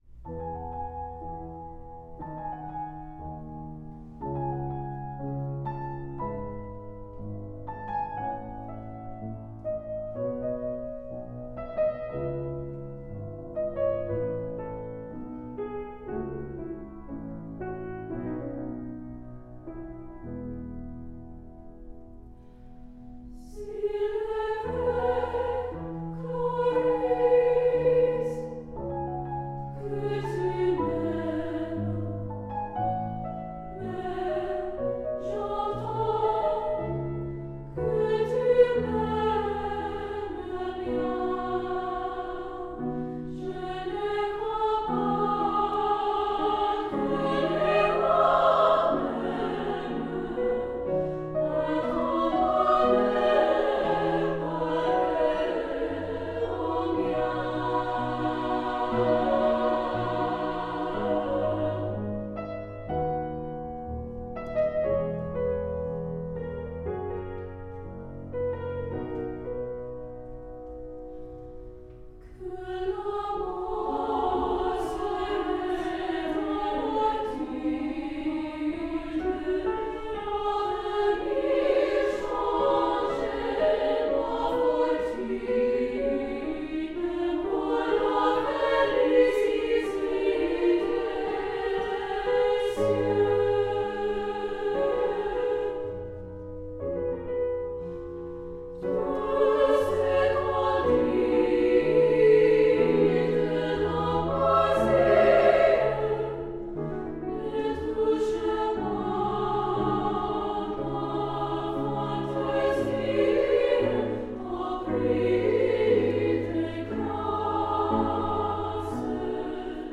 Voicing: "SSA"